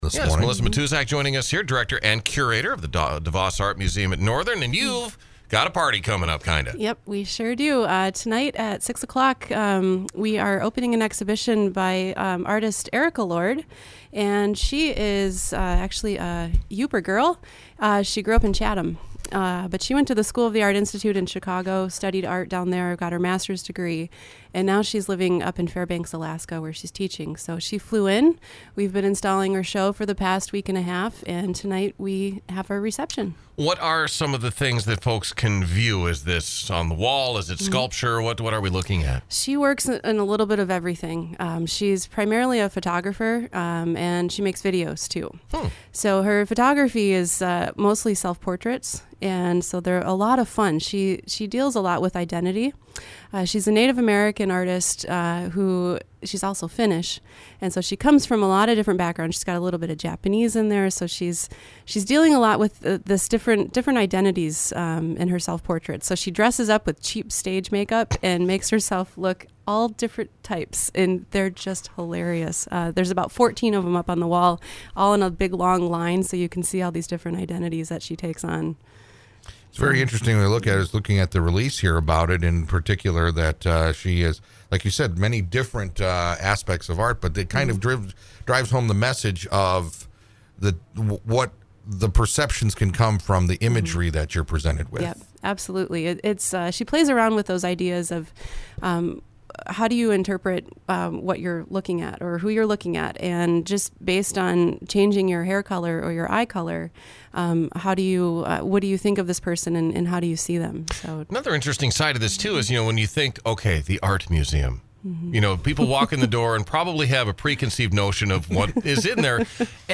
NewsOld Interviews Archive